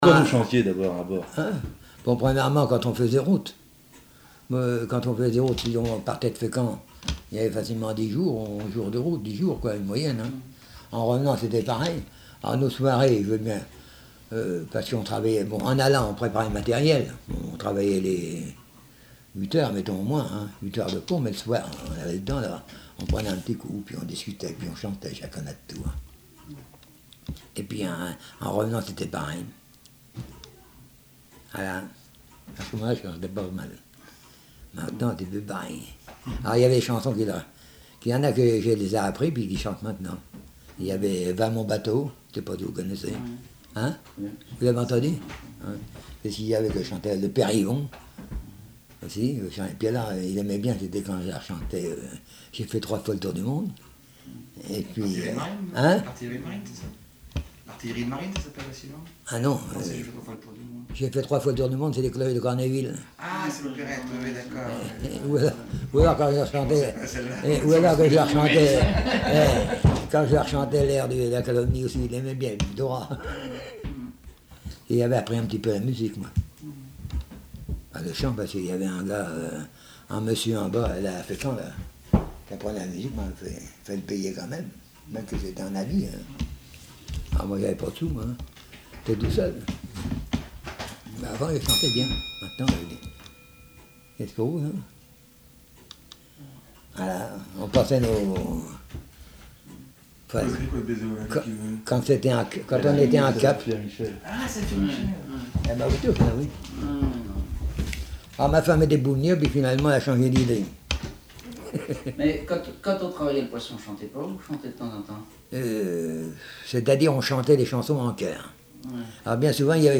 Témoignages sur la pêche à Terre-Neuve et chansons
Catégorie Témoignage